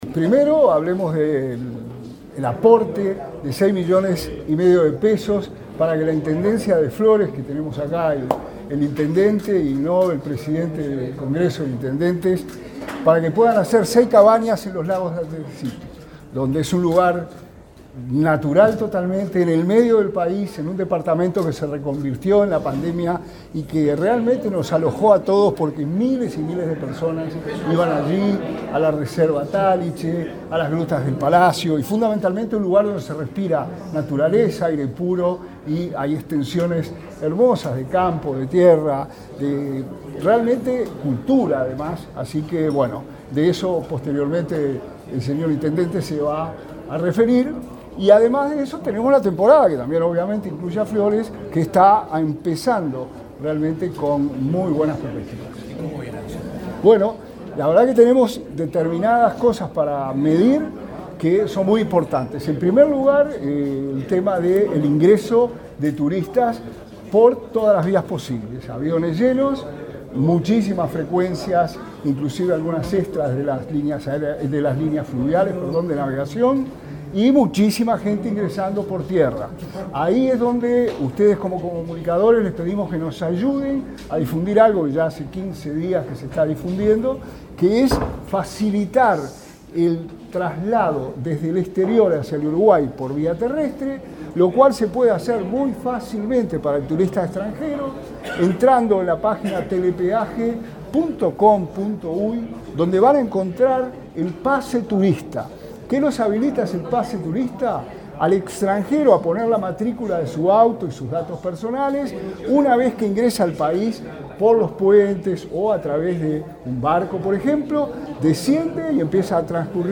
Declaraciones a la prensa del subsecretario de Turismo, Remo Monzeglio
El intendente de Flores, Fernando Echeverría; el subsecretario de Turismo, Remo Monzeglio, y el secretario de Presidencia de la República, Álvaro Delgado, participaron del lanzamiento de la 30,º edición del Festival del Lago Andresito le Canta al País, realizado este martes 27 en Montevideo. Luego, Monzeglio dialogó con la prensa.